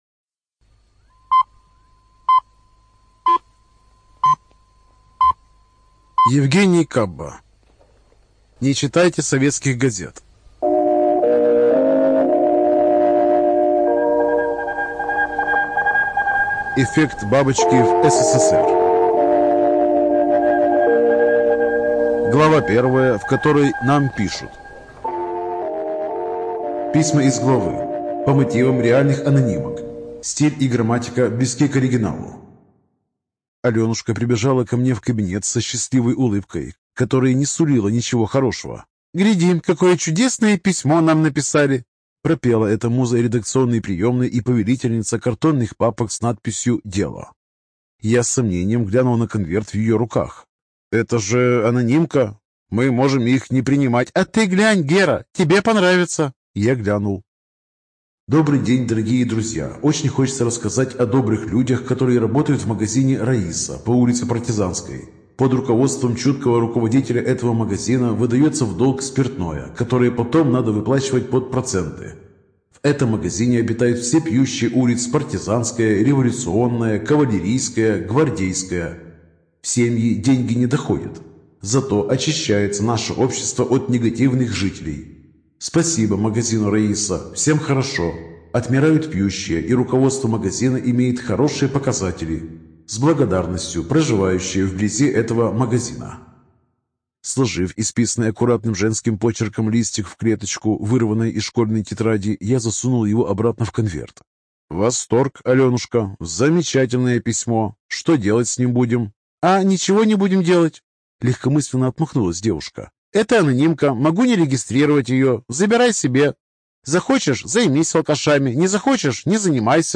Студия звукозаписиАСТ